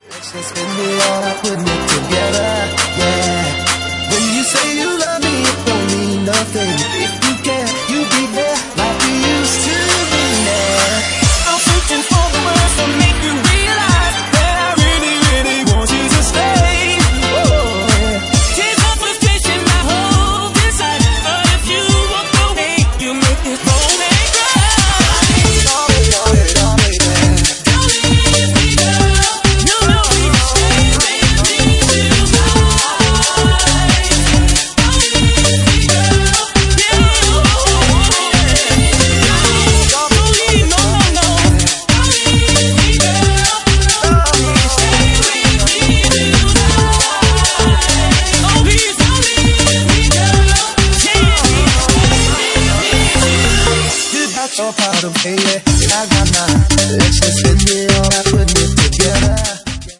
Genre:Bassline House
Bassline House at 135 bpm